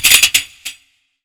TC2 Perc11.wav